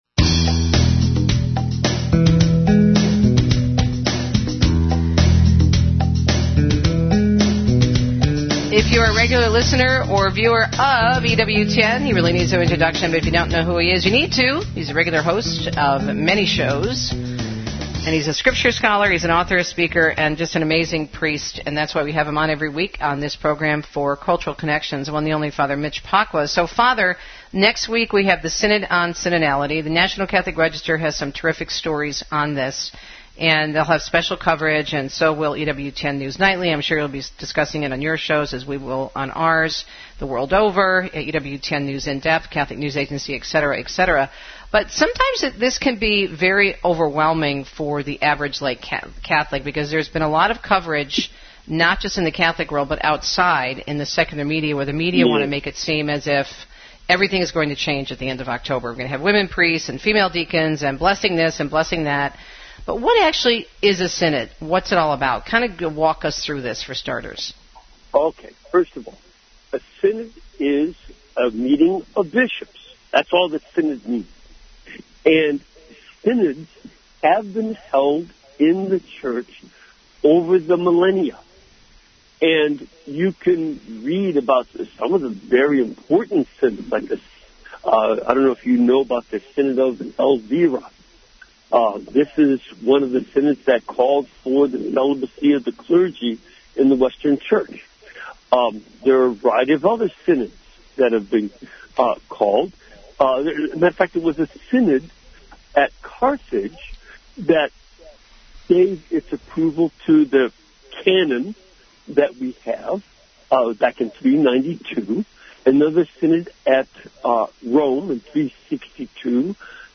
Radio interviews and excellent articles that help you make sense of the happenings in Rome for the Synod on Synodality.